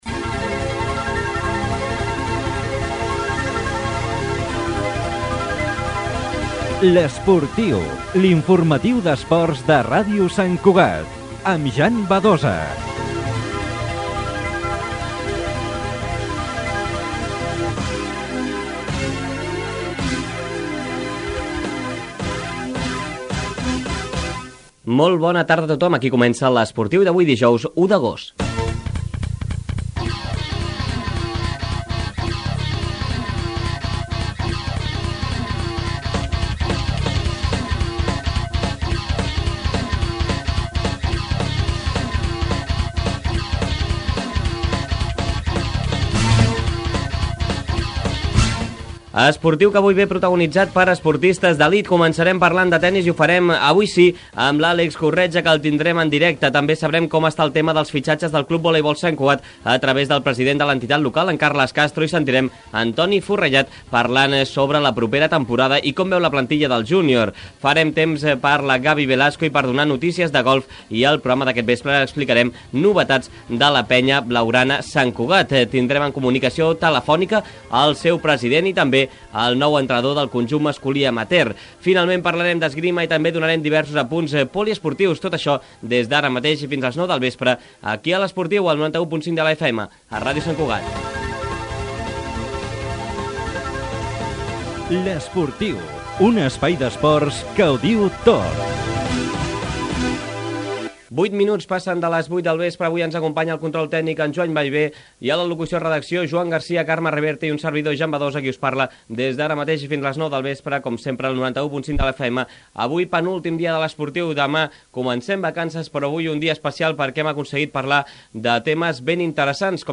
Introducció, sumari, entrevista al tenista Àlex Corretja i comiat.
Esportiu